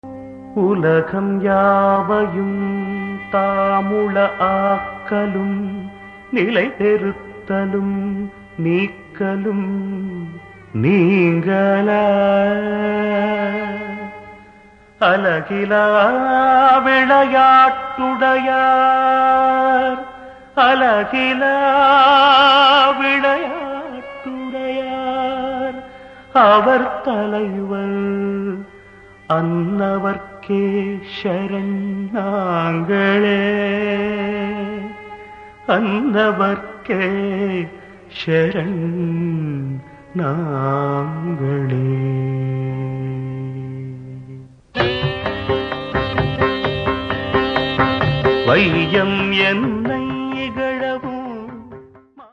light & sound program